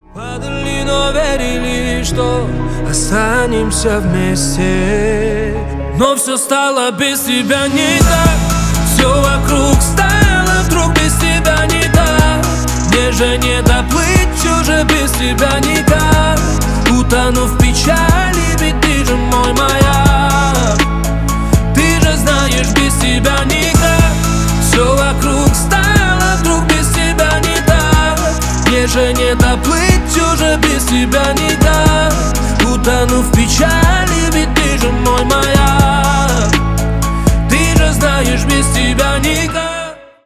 Поп Музыка
грустные # кавказские